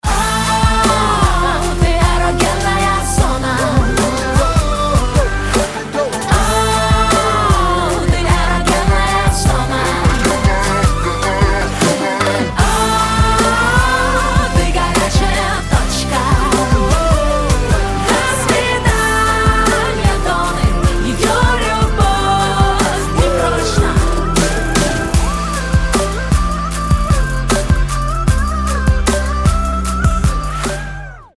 • Качество: 320, Stereo
громкие
RnB